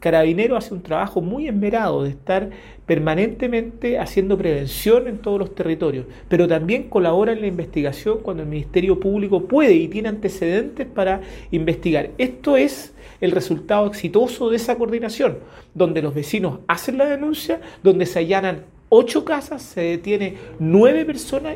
Por su parte, el Delegado Presidencial, José Montalva, resaltó la importancia de hacer las denuncias, para arribar a resultados como en este caso.